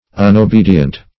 Unobedient \Un`o*be"di*ent\, a.